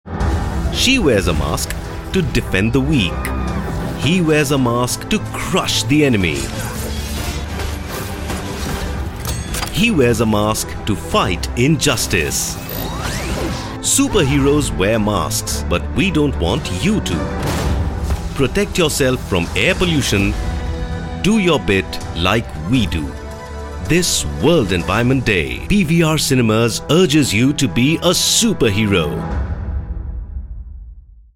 His voice can be described as: deep, baritone, warm, friendly, intense, informed, reassuring, joyful, commanding.
English Sample